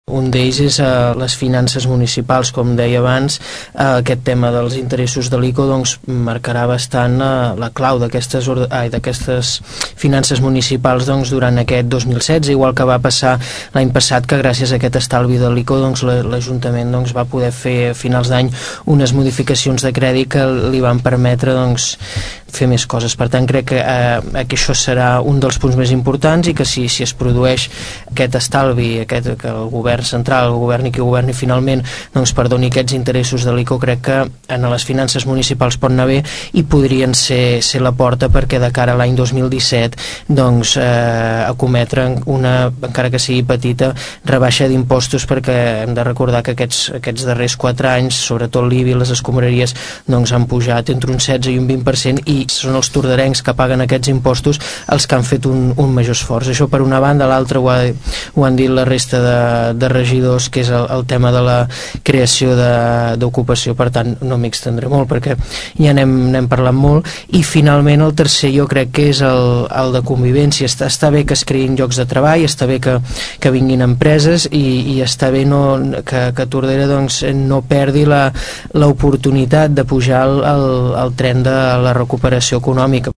La tertúlia de polítics se celebra cada mes, la següent setmana després del plenari municipal.